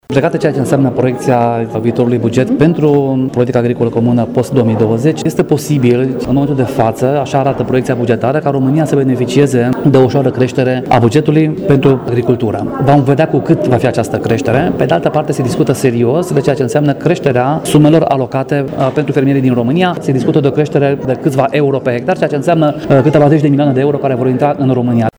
În schimb, a fost prezent eurodeputatul Daniel Buda, membru al Comisiei de Agricultură şi Dezvoltare Rurală din Parlamentul European, care a adus vești bune de la Bruxelles…